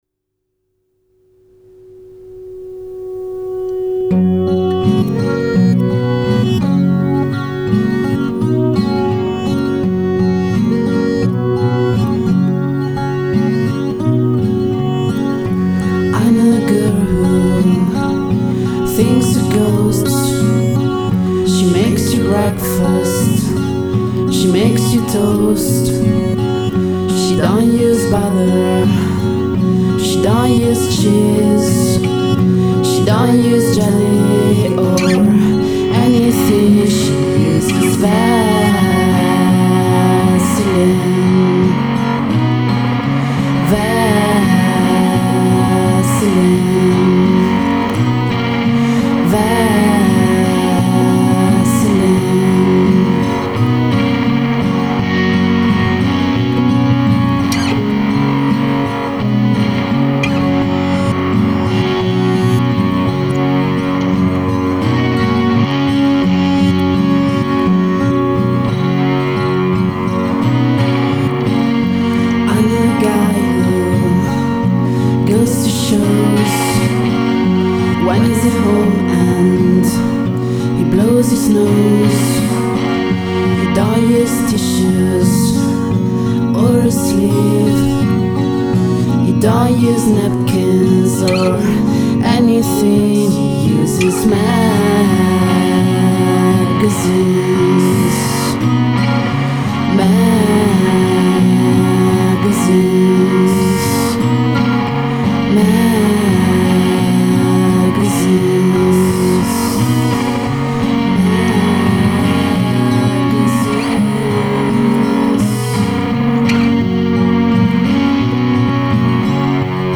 in bilico tra pop, rock e "midwest highways"